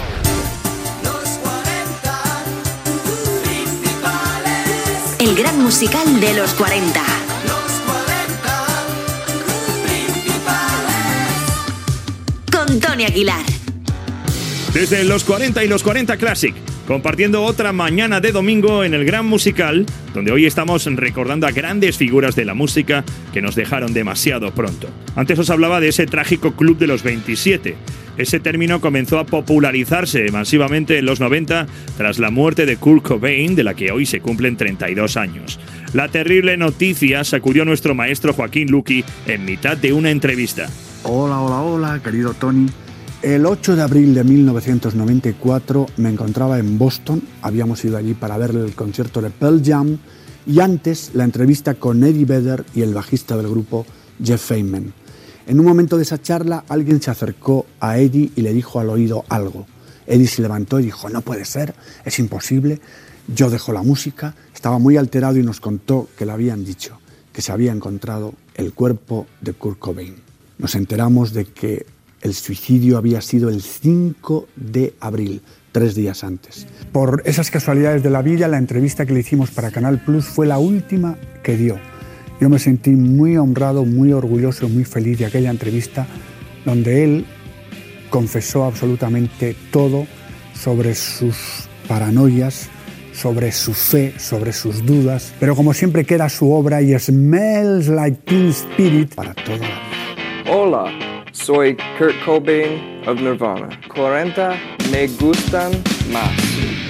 Indicatiu del programa, comentari de Joaquín Luqui, sobre la mort del cantant Kurt Cobain.
Musical